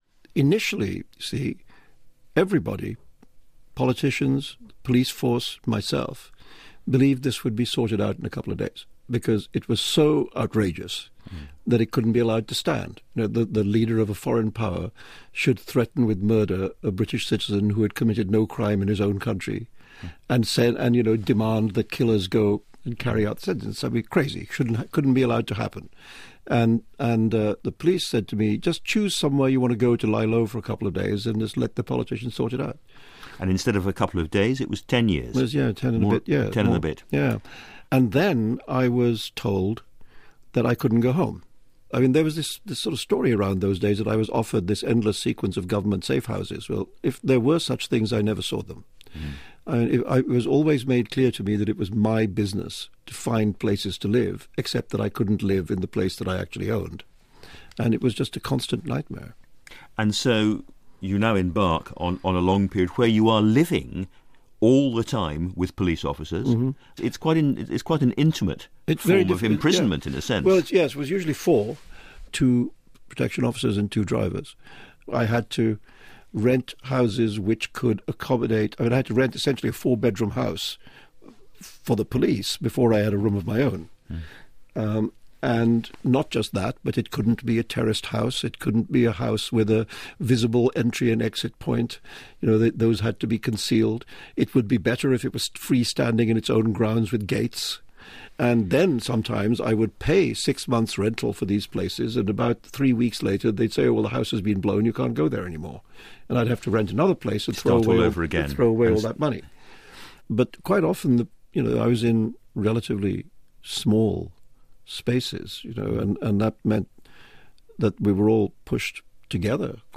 Author Salman Rushdie speaks to Start the Week’s Andrew Marr about the ten-years he spent in hiding after he was sentenced to death by the Ayatollah Khomeini in 1989 for writing The Satanic Verses.